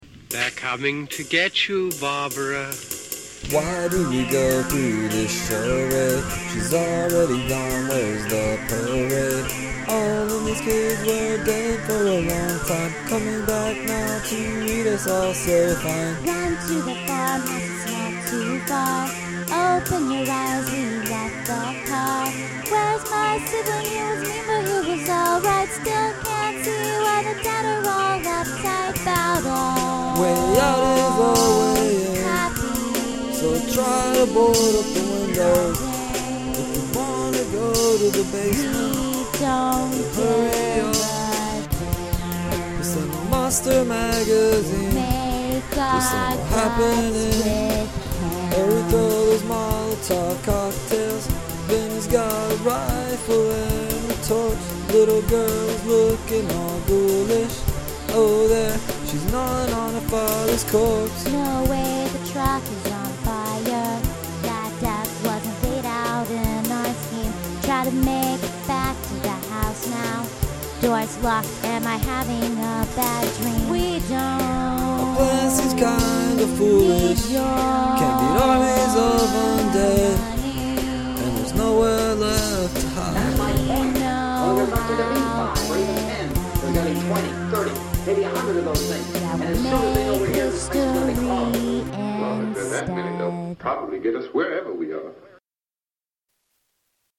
lo-fi
synthesizer